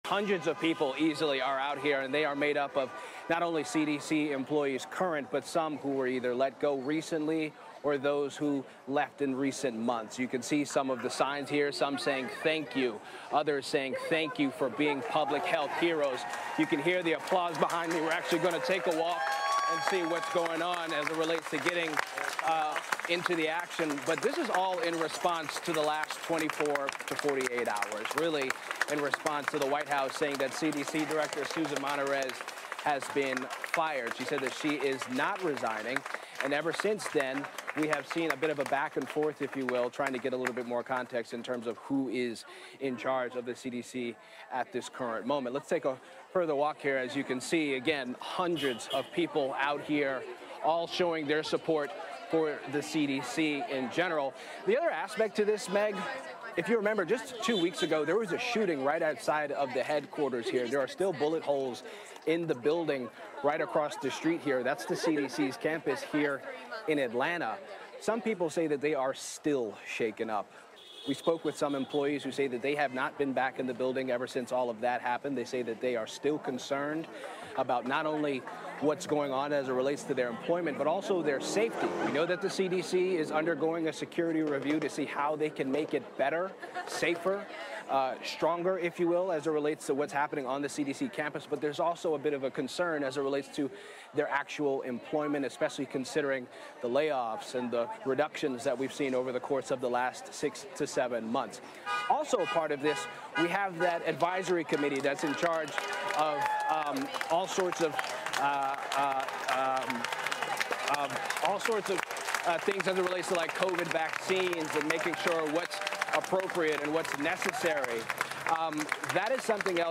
is in Atlanta where hundreds have gathered.